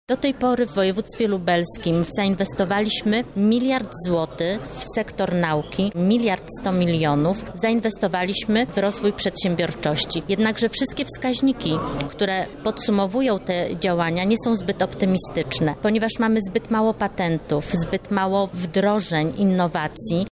„Nie wszystko poszło tak jak planowaliśmy.” – mówi Bożena Lisowska, radna lubelskiego sejmiku wojewódzkiego.